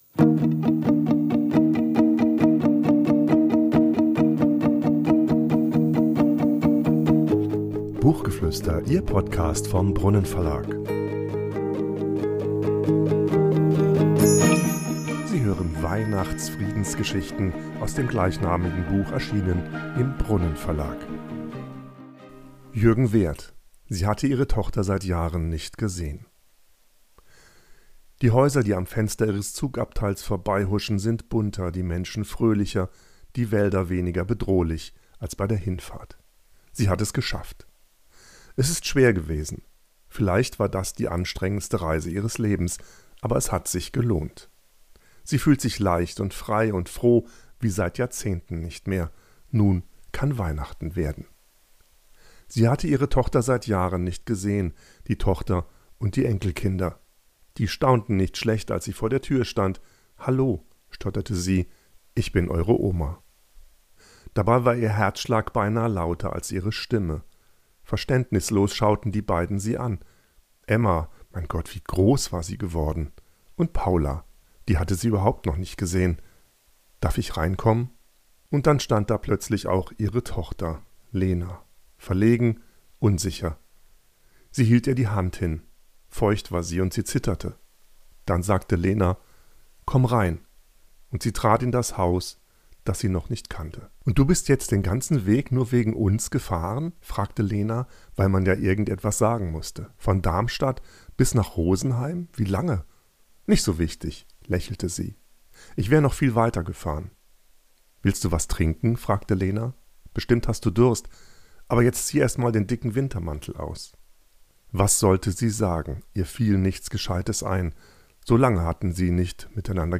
»WeihnachtsFriedensGeschichten« vorliest.